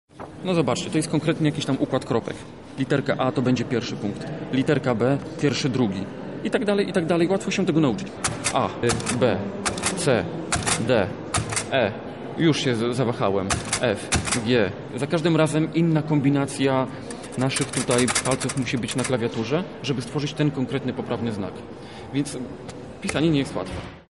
Na Katolickim Uniwersytecie Lubelskim odbył się Dzień Białej Laski.